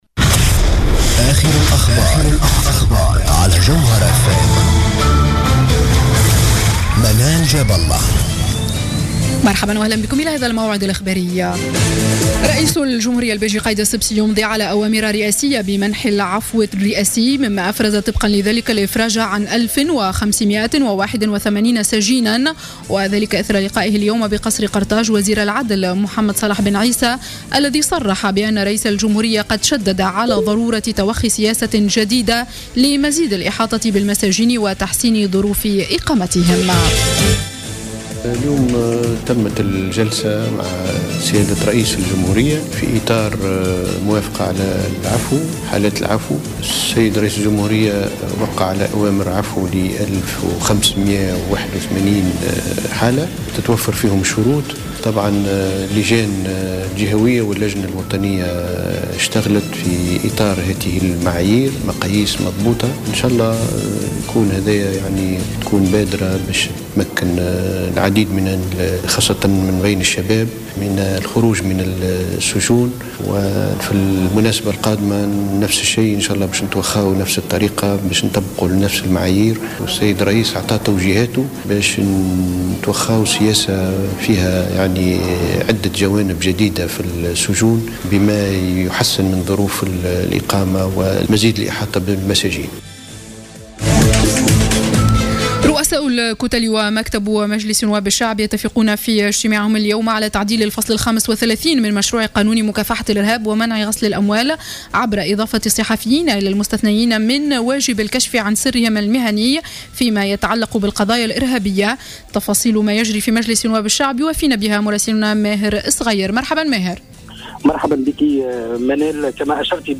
نشرة أخبار السابعة مساء ليوم الجمعة 24 جويلية 2015